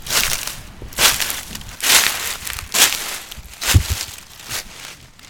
낙엽.mp3